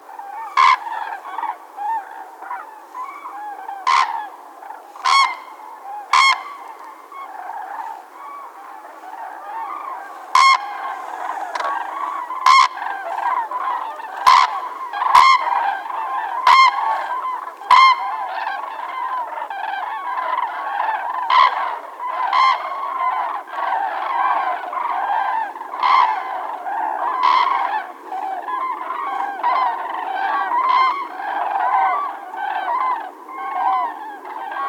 Вы можете слушать онлайн или скачать знаменитое курлыканье, крики и звуки общения этих грациозных птиц в формате mp3.
Шум взлетающего журавля